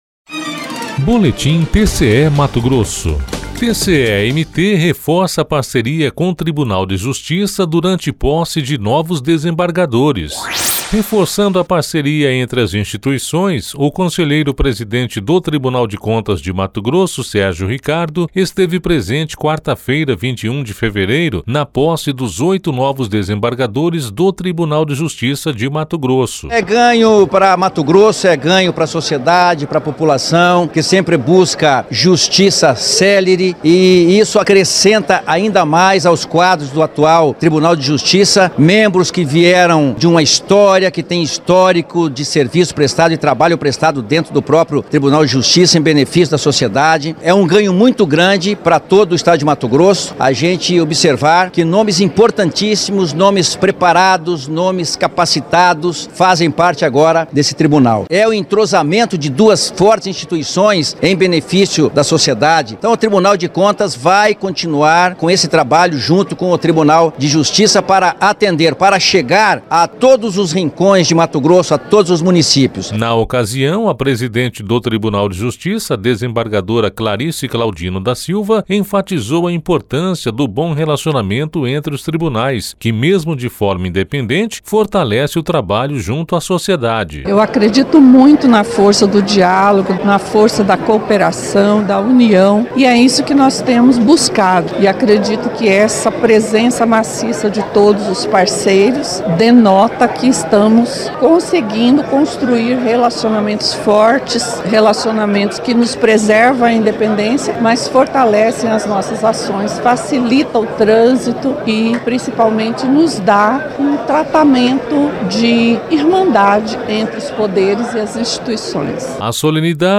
Sonora: Sérgio Ricardo - conselheiro-presidente do TCE-MT
Sonora: Clarice Claudino da Silva – desembargadora presidente do TJ-MT
Sonora: Mauro Mendes – governador de MT